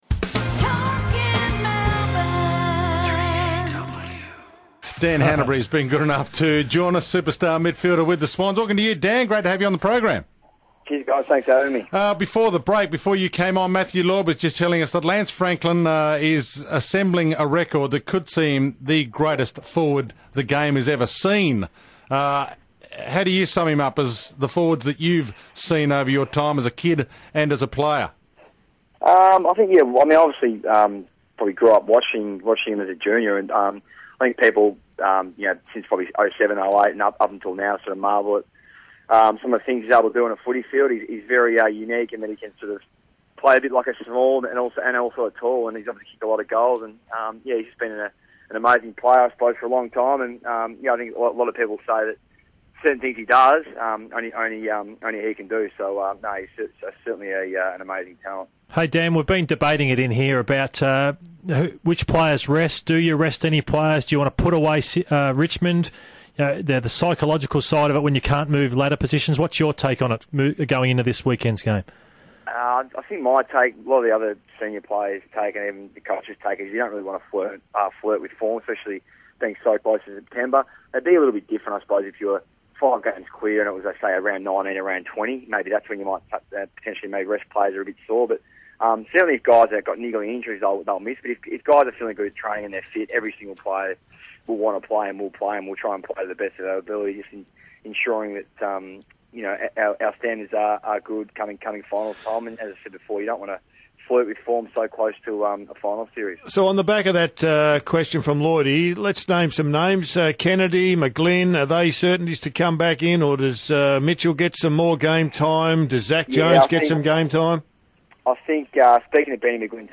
Sydney Swans midfielder Dan Hannebery appeared on 3AW on Monday August 25, 2014